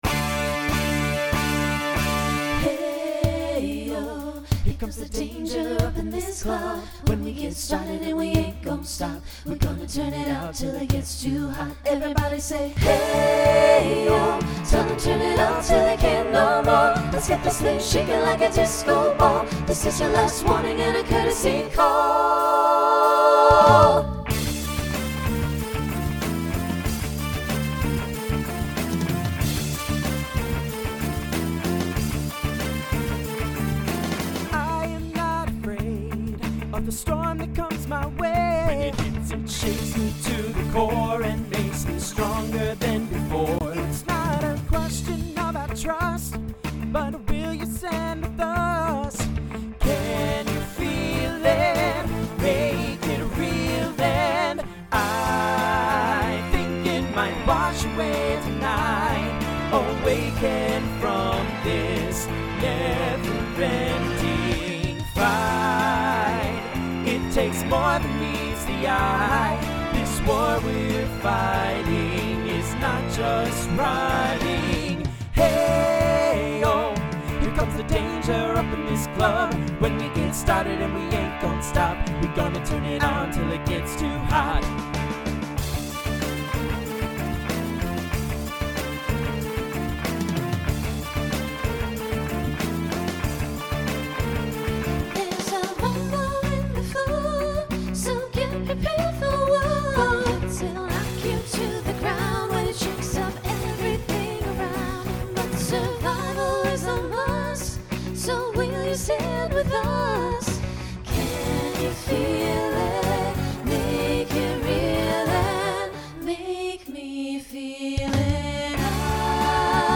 Voicing Mixed Instrumental combo Genre Rock